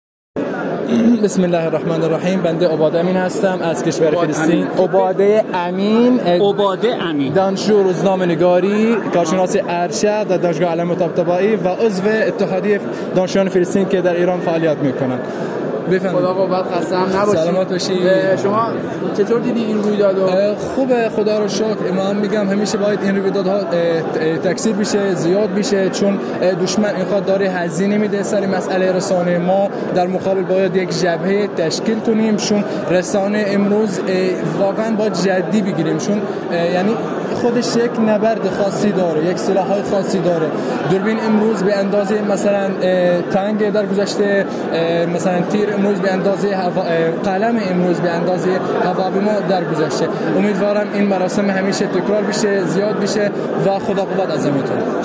یک دانشجوی فلسطینی رشته‌ روزنامه‌نگاری گفت: شکل دیگری از جنگ نرم را شاهد هستیم که فضای مجازی یکی از مهمترین ابزارهای آن است.